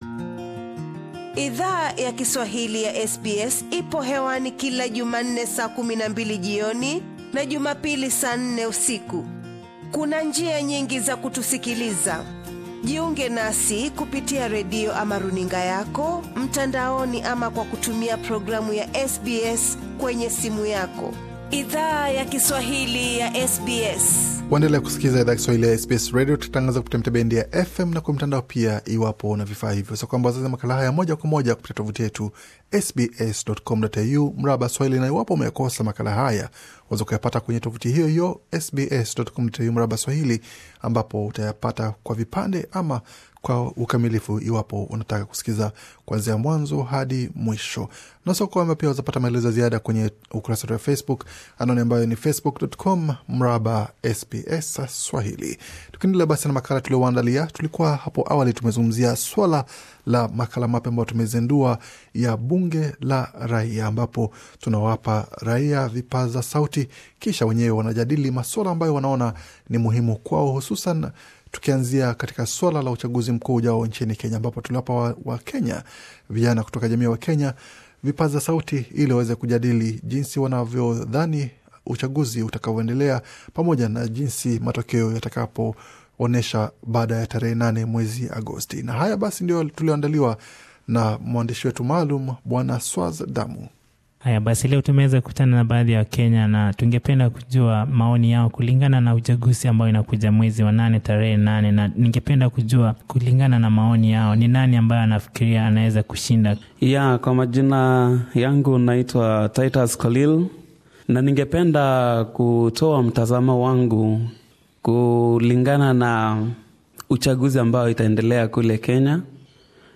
Joto la kampeni za urais linapo endelea kuongezeka nchini Kenya, baadhi ya wanachama wa jamii yawa Kenya wanao ishi NSW, walishiriki katika makala mapya ya SBS Swahili, 'Bunge la Raia', wali toa maoni yao kuhusu juhudi za wagombea wa Urais.